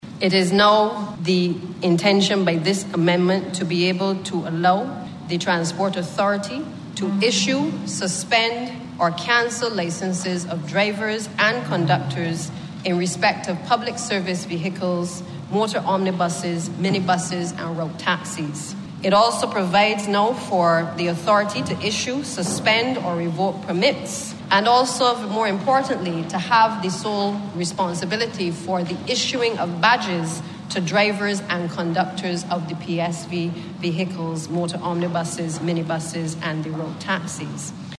Acting Prime Minister and Minister of Transport, Santia Bradshaw.